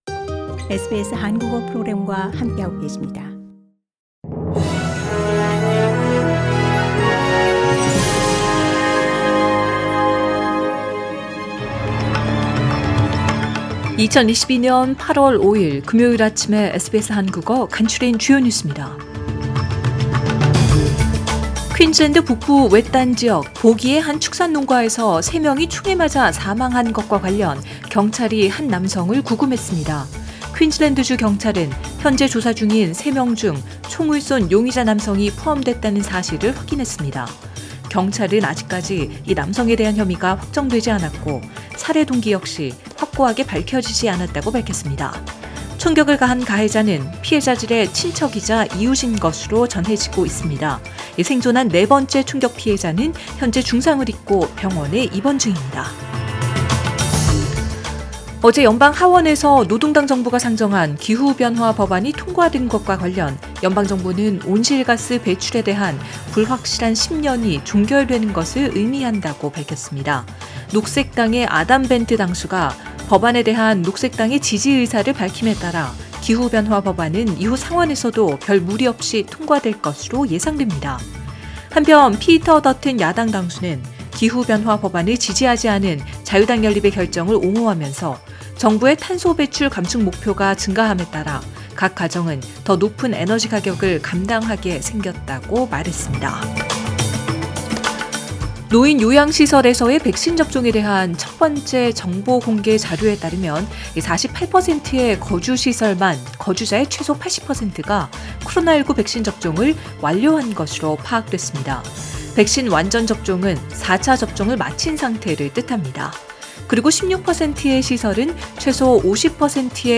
SBS 한국어 아침 뉴스: 2022년 8월 5일 금요일